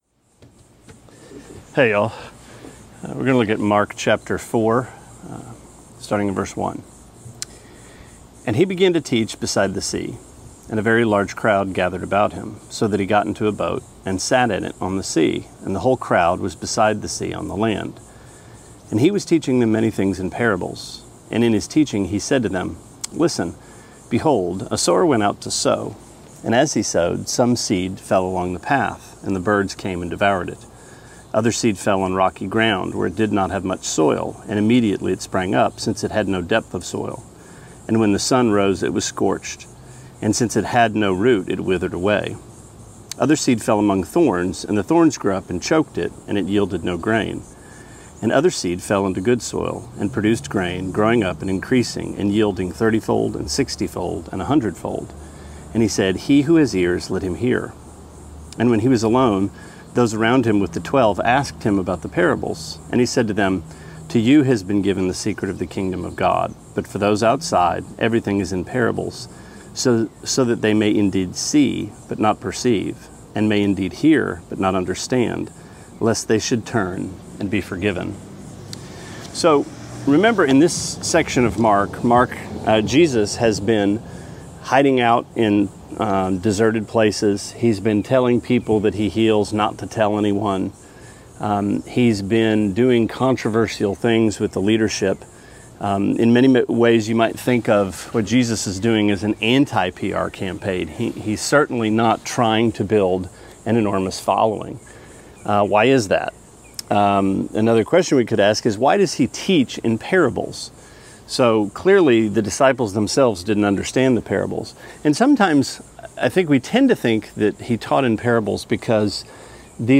Sermonette 6/26: Mark 4:1-12: Secrets